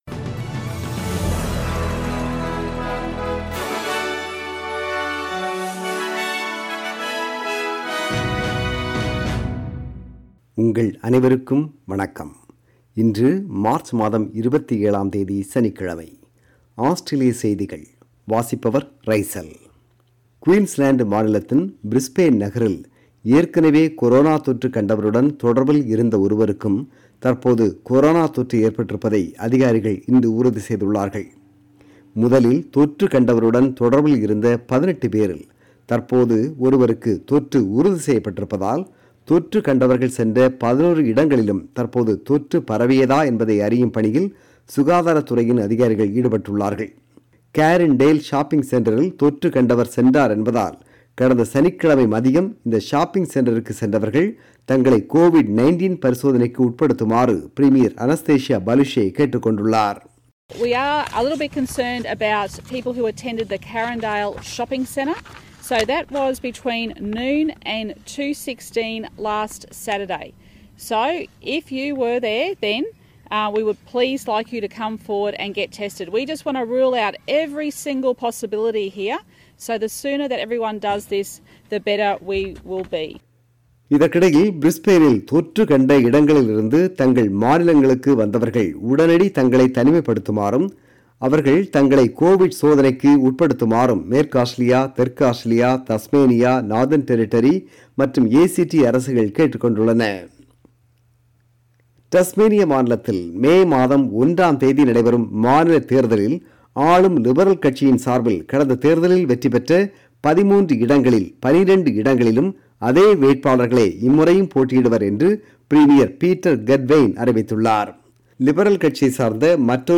Australian News: 27 March 2021 – Saturday